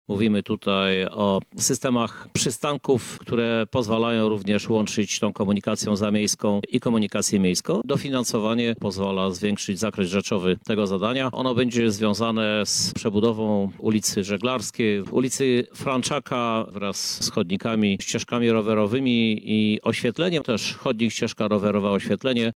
Staramy się spoglądać na usługę komunikacyjną w obszarze funkcjonalnym, z uwzględnieniem sąsiednich gmin – mówi prezydent Lublina Krzysztof Żuk: